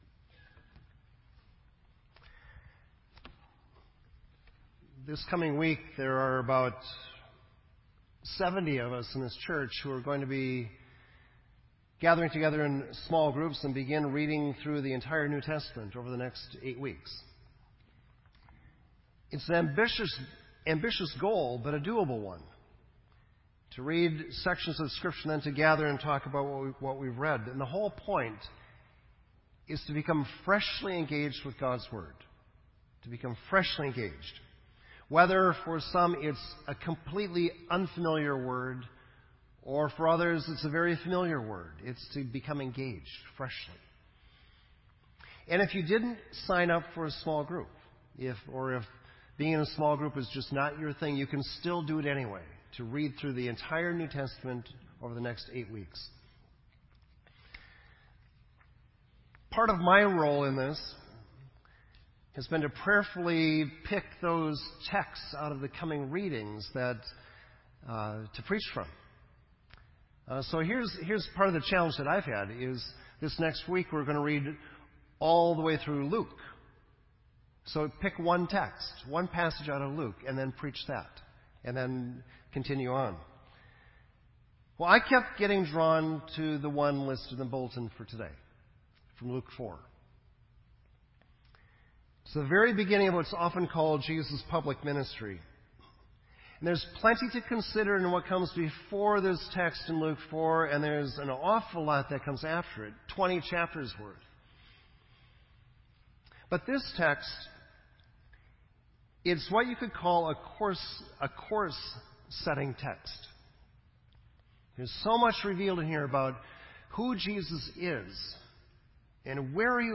This entry was posted in Sermon Audio on October 3